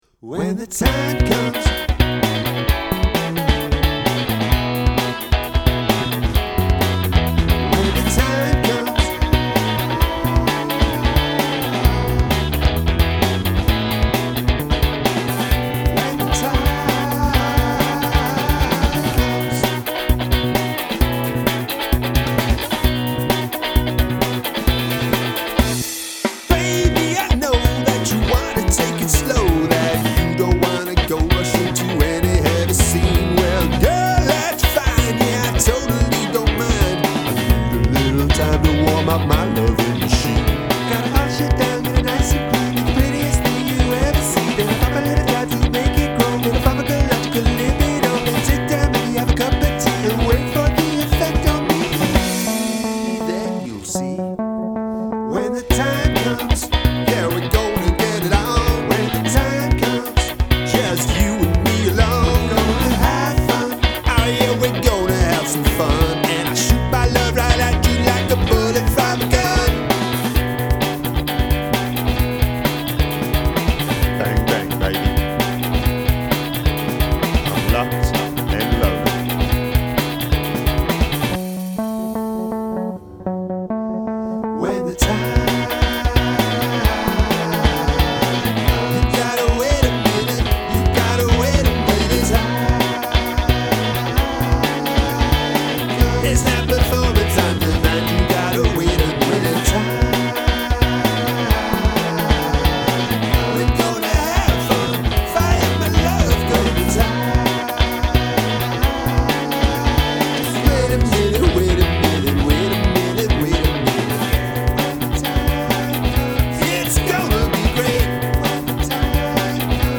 Use of the sound of gunfire